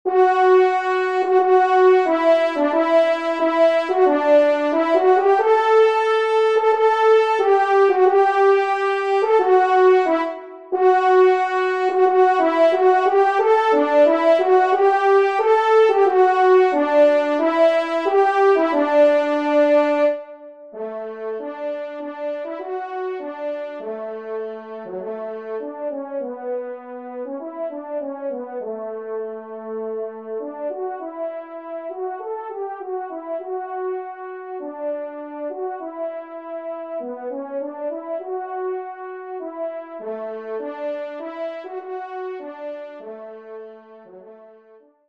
Pupitre 1° Cor (en exergue)